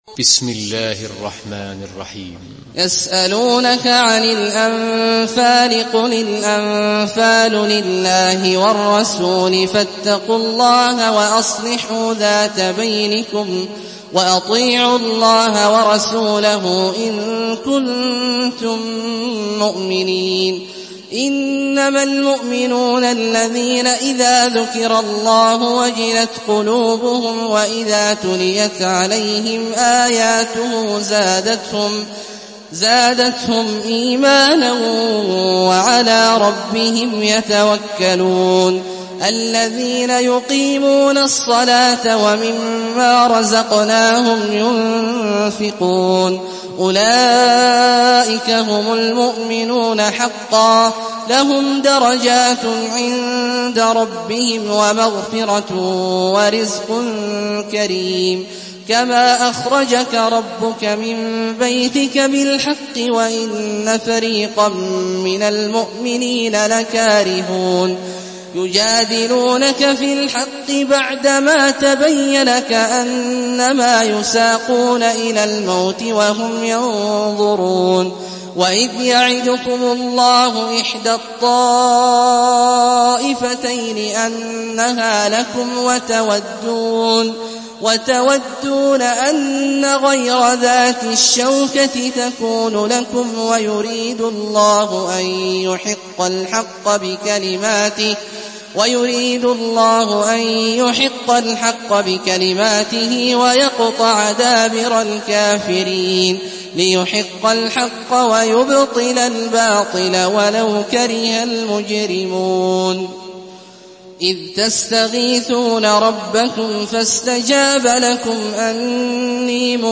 Surah Enfal MP3 by Abdullah Al-Juhani in Hafs An Asim narration.
Murattal Hafs An Asim